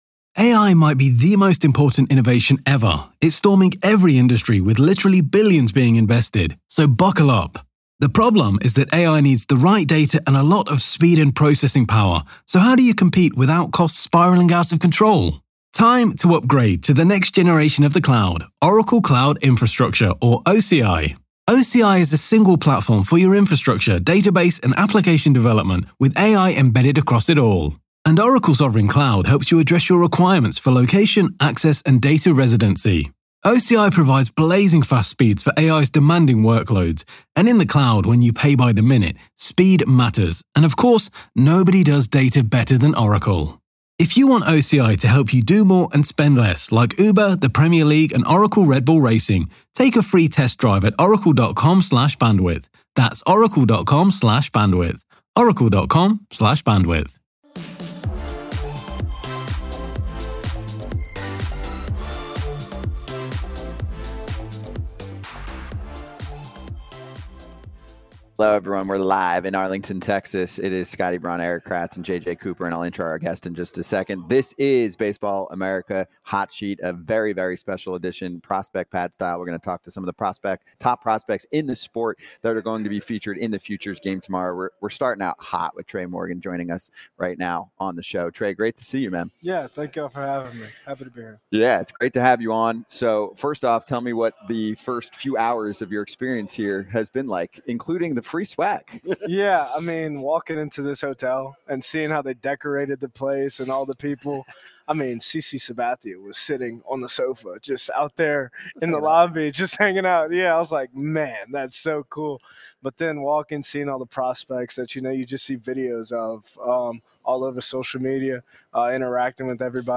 Futures Game Stars Join Hot Sheet, Mock Draft 6.0 | Live From Arlington